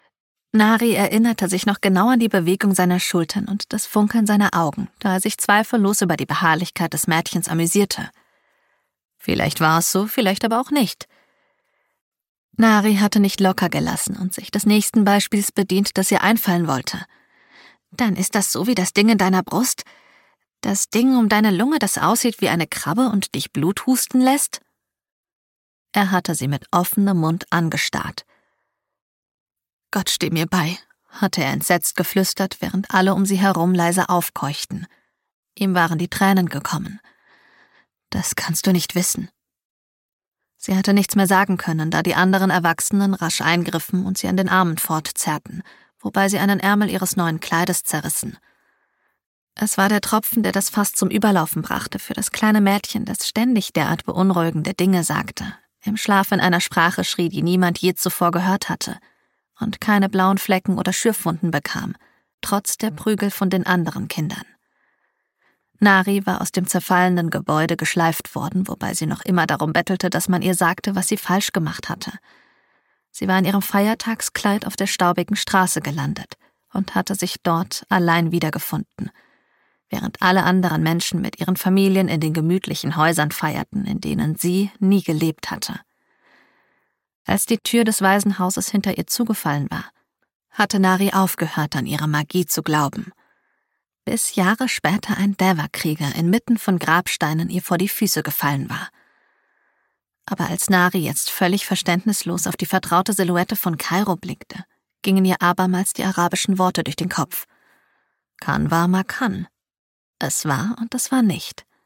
dunkel, sonor, souverän, markant, sehr variabel
Hörbuch Fantasy
Audiobook (Hörbuch), Scene